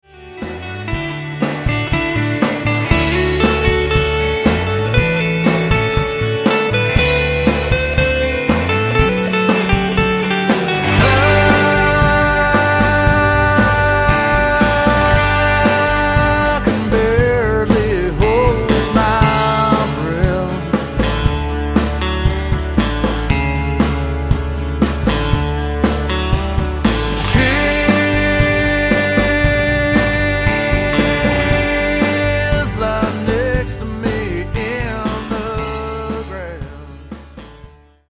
(a Post-Ironic Americana Opera)